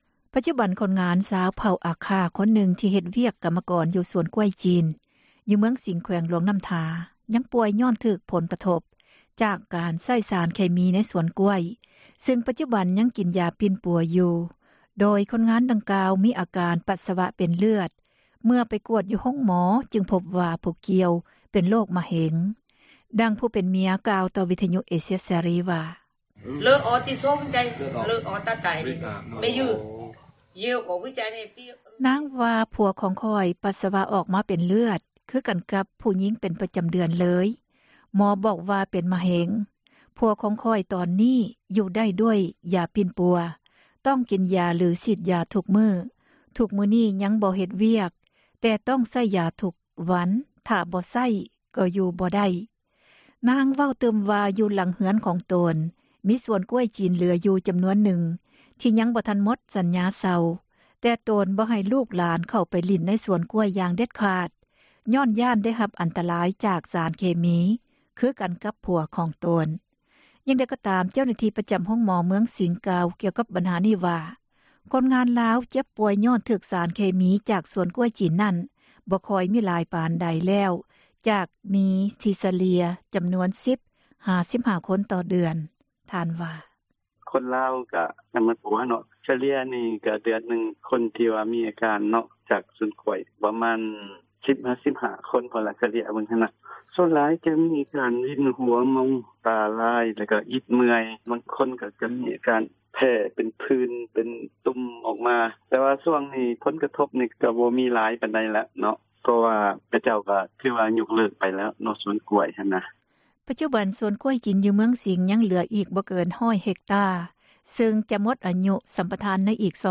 ມ.ສິງ ຄົນງານສວນກ້ວຍ ລົ້ມປ່ວຍ — ຂ່າວລາວ ວິທຍຸເອເຊັຽເສຣີ ພາສາລາວ